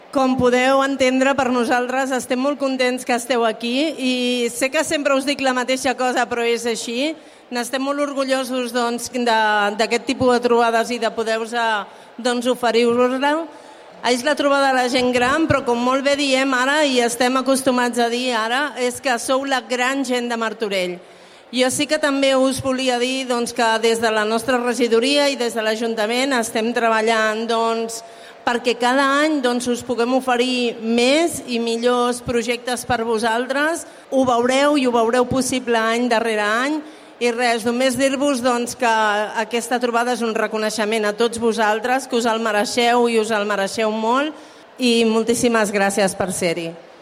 Montserrat Salas, regidora de Gent Gran de l'Ajuntament
Parlaments-Montse-Salas-Trobada-Tardor-Gent-Gran.mp3